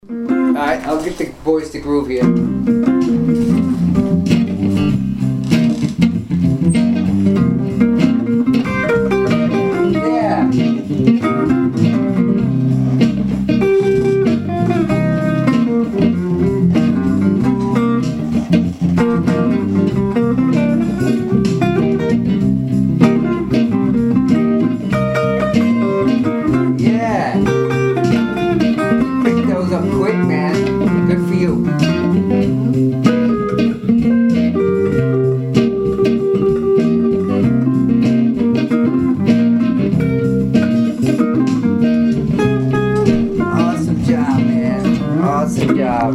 Soloing, Embellishments to the Pentatonics, Double-Stops - Guitar Lessons in Myrtle Beach, SC
07-double-stops-implementation.mp3